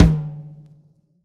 drum-hitclap.ogg